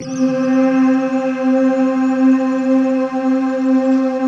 Index of /90_sSampleCDs/Optical Media International - Sonic Images Library/SI1_RainstickChr/SI1_RainstickMix